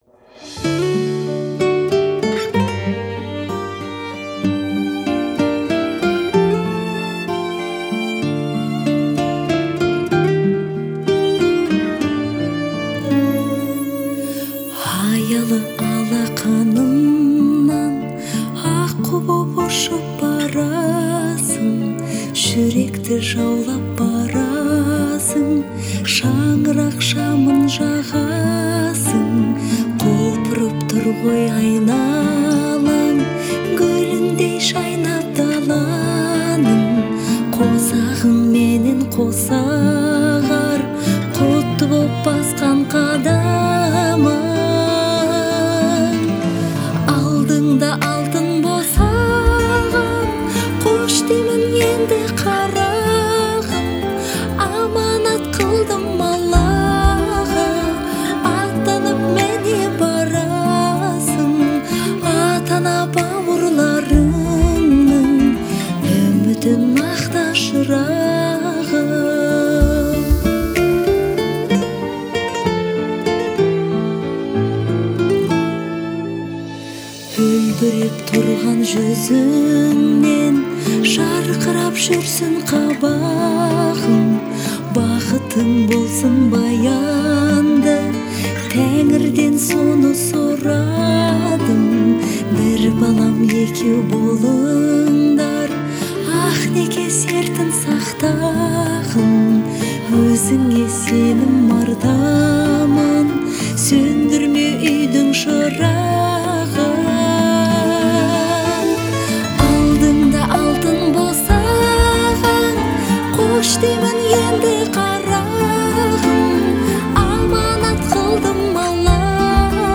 это яркая и мелодичная песня в жанре поп
обладает мощным голосом и выразительной манерой исполнения